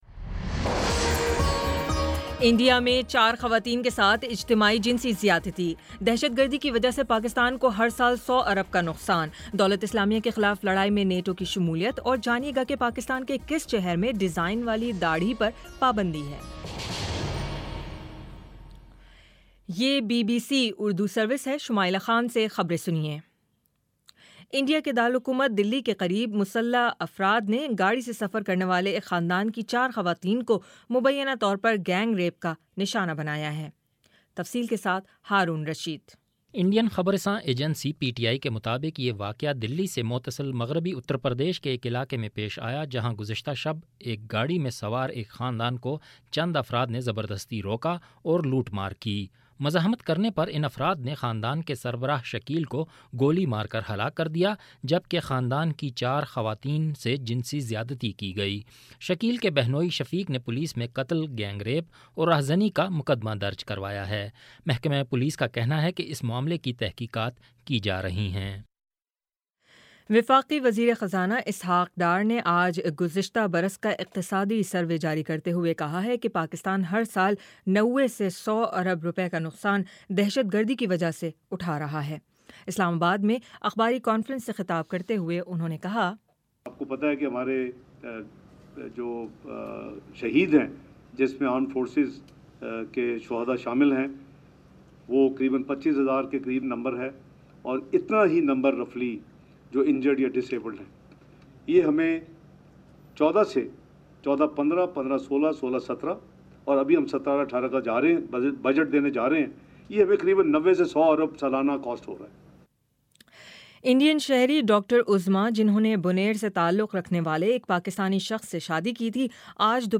مئی 25 : شام پانچ بجے کا نیوز بُلیٹن